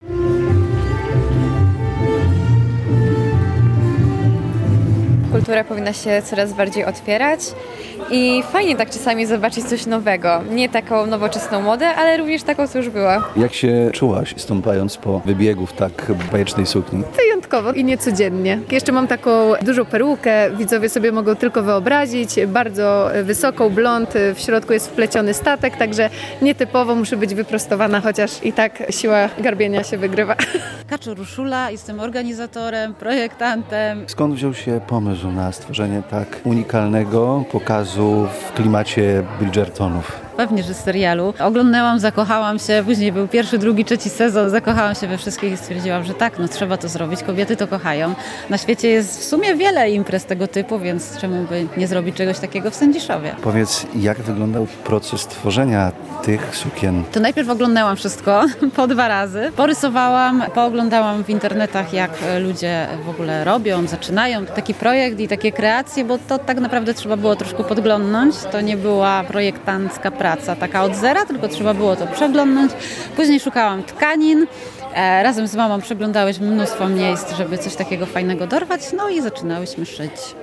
Plac targowy w Sędziszowie Małopolskim na jeden wieczór zamienił się w pałacowy ogród.
Nie zabrakło muzyki na żywo, stylowych fryzur i dekoracji rodem z epoki.